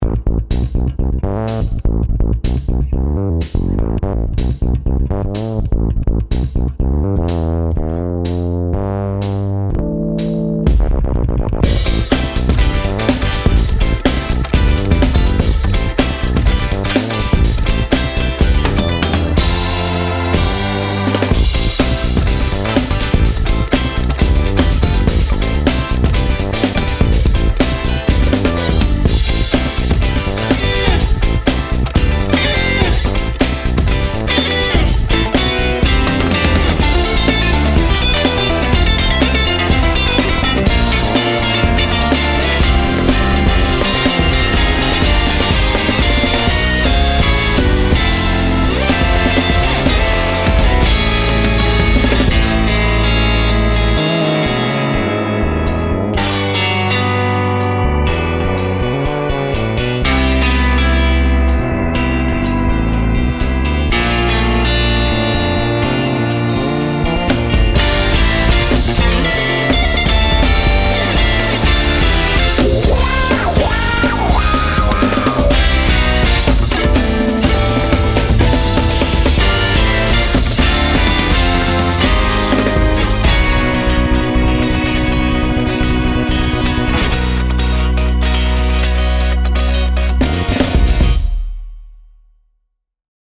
「インスト部門」最優秀作品です。